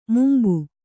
♪ mumbu